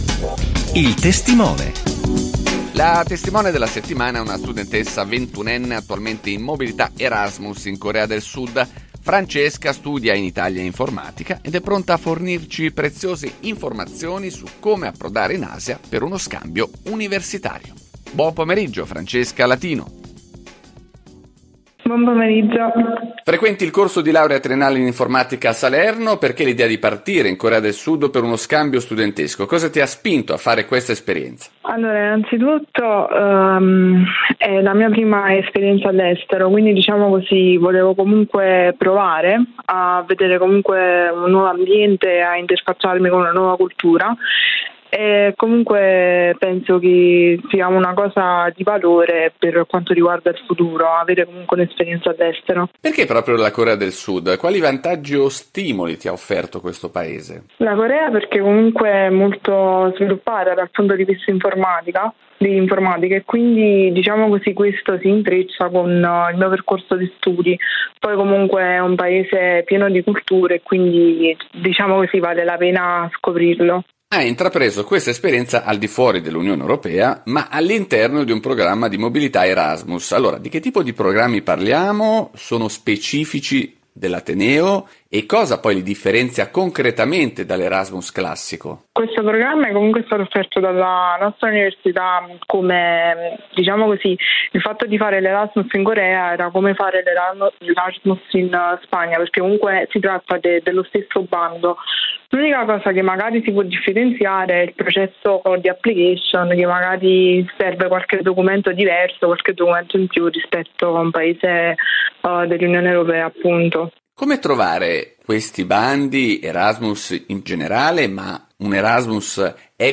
F.-Erasmus-Corea-sud.mp3